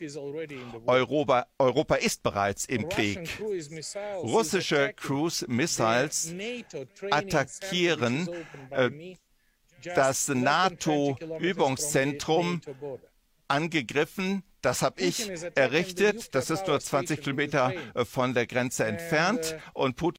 Naja und deswegen gefiel mir im Interview die Stelle am besten, an der Poroschenko live auf ZDF anklagend verkündete, dass die Russen einen NATO-AUSBILDUNGSSTÜTZPUNKT zerstörten, den er unter seiner Präsidentschaft liebevoll aufgebaut habe...